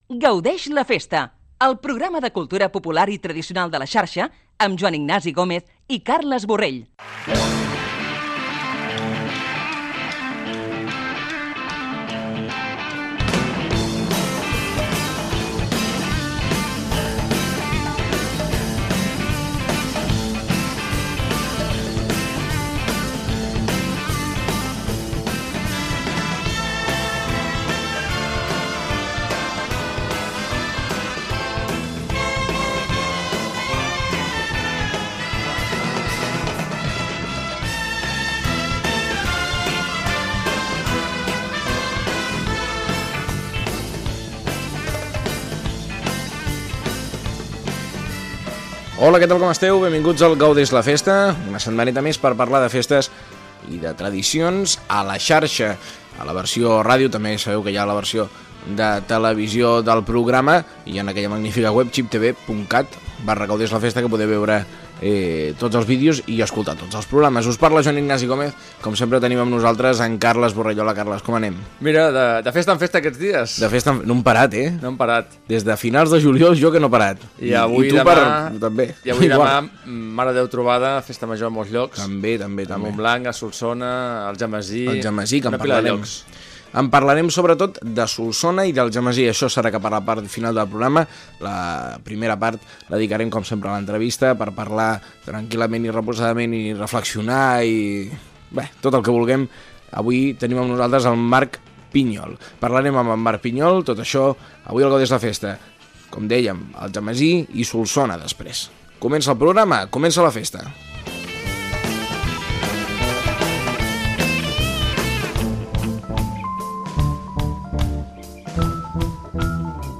Careta del programa i presentació del programa dedicat a les festes i tradicions de Catalunya